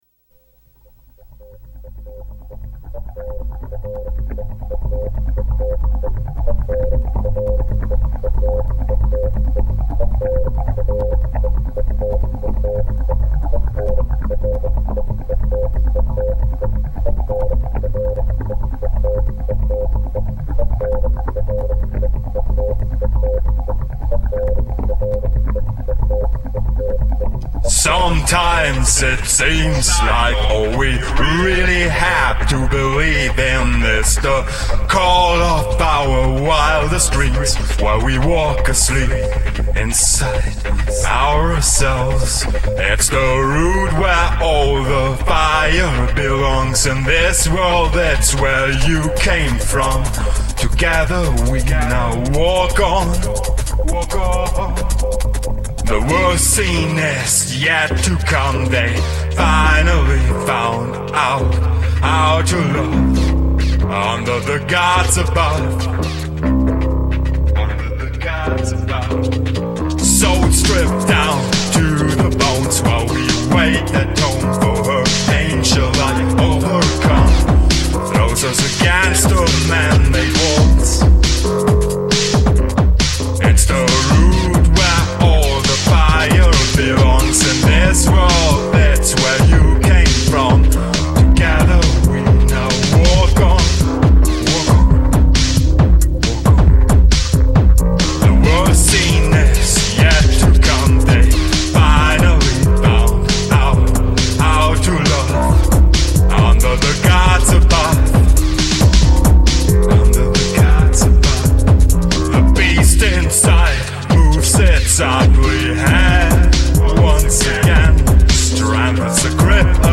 a kind of an Industrial-Techno-Goth-IDM trio from Germany.